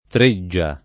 treggia [ tr %JJ a ] s. f.; pl. ‑ge